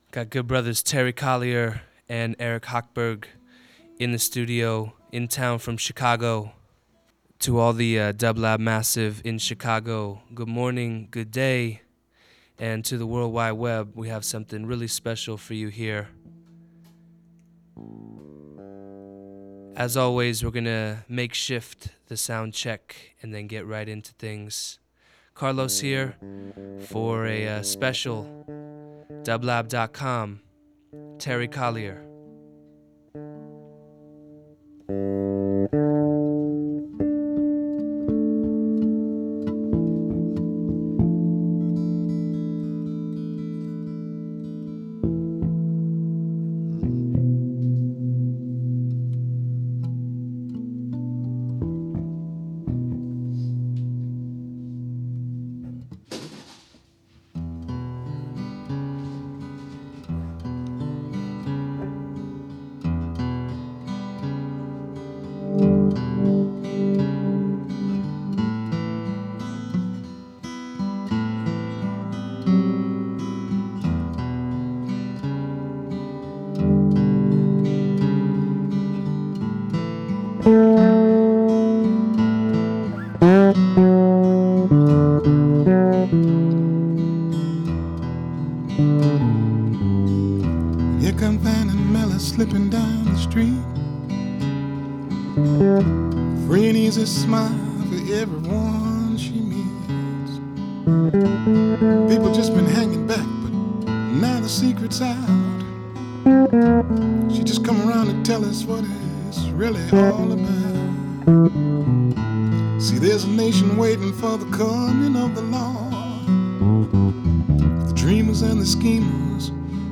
Folk Funk/Soul Jazz